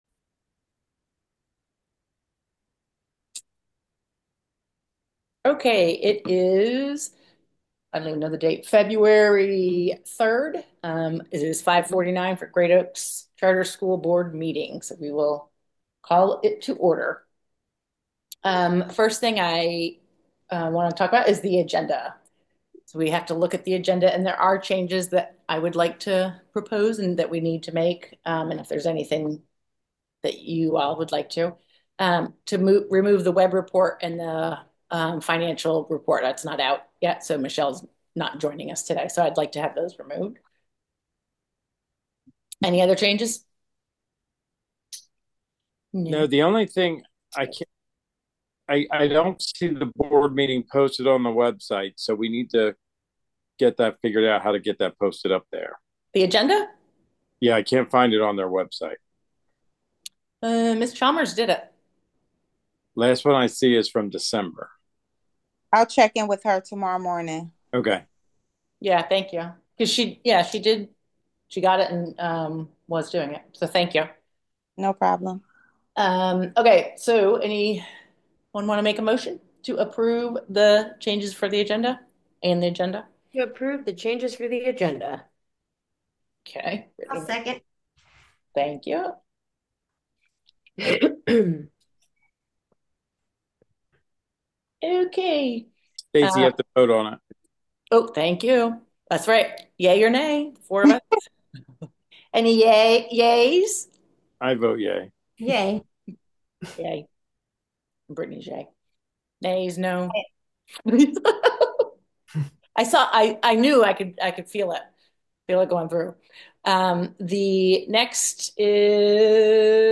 GOCS-Board-Mtg-Feb-2025.m4a